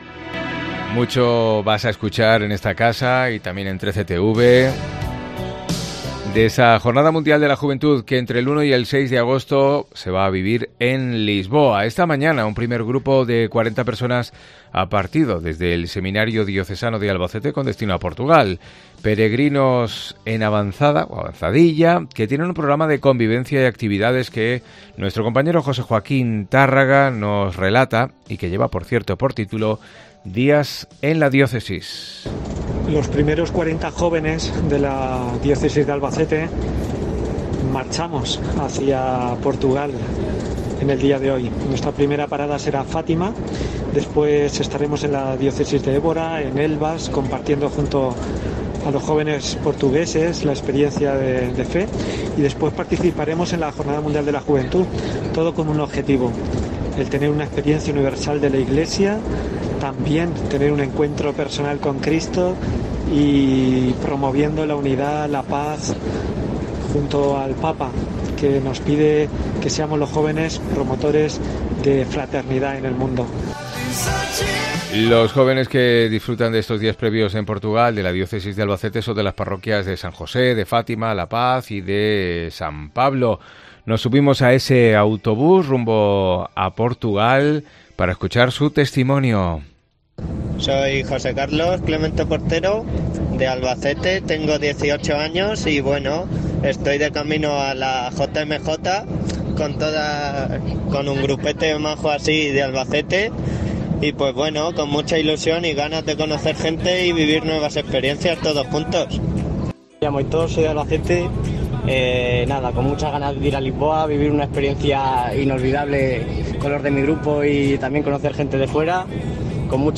Reportaje con testimonios de los primeros 'enviados' de la Diócesis de Albacete.